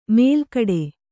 ♪ mēlkaḍe